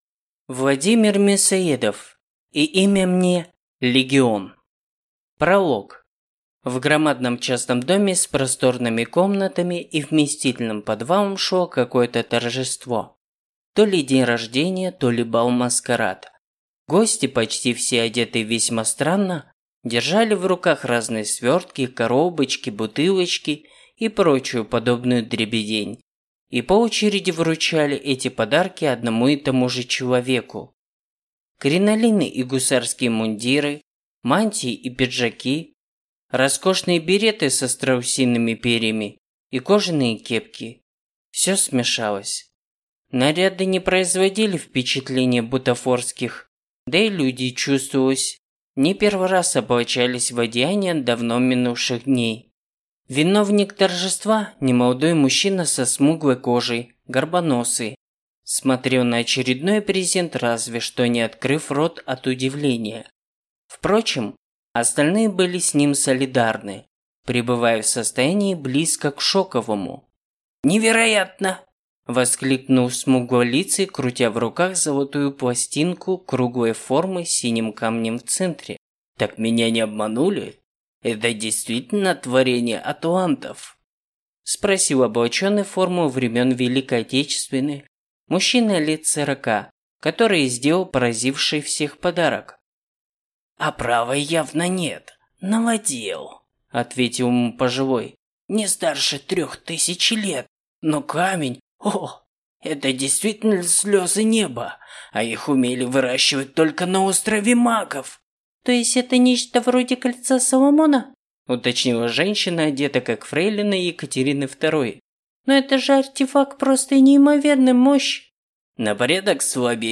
Аудиокнига И имя мне – Легион | Библиотека аудиокниг